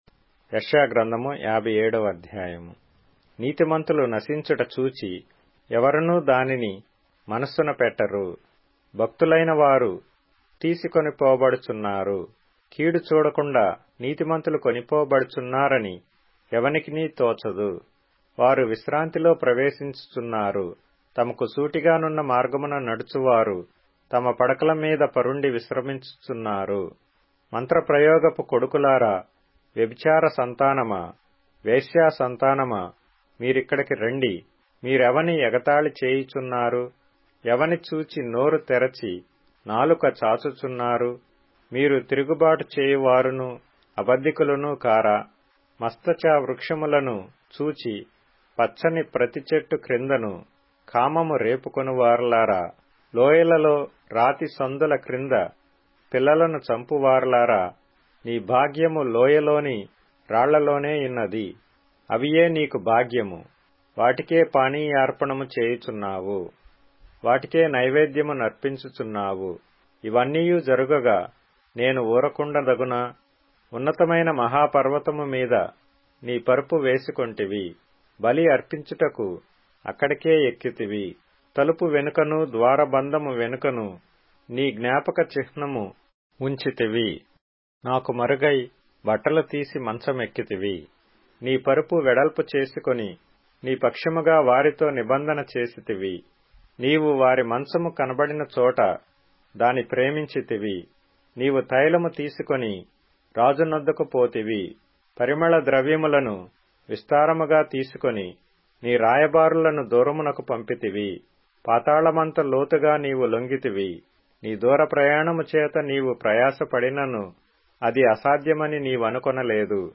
Telugu Audio Bible - Isaiah 14 in Orv bible version